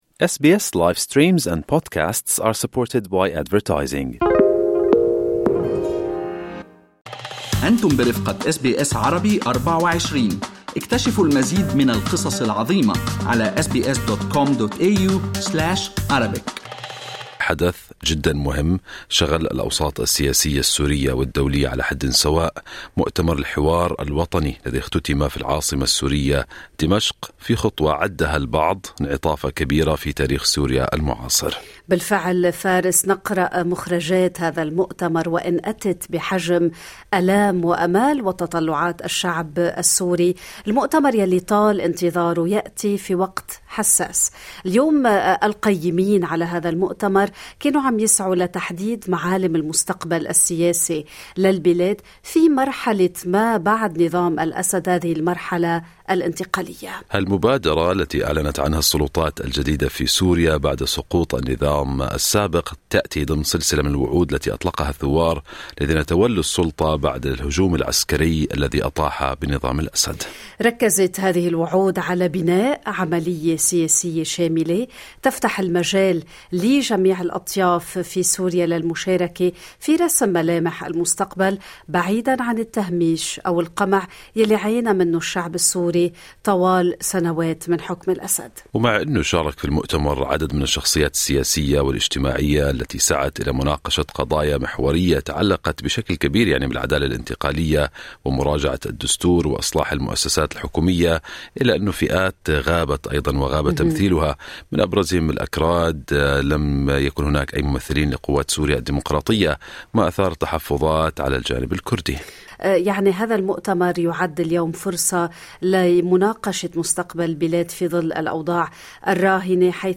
Damascus based journalist